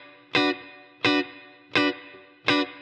DD_TeleChop_85-Bmin.wav